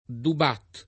[ dub # t ]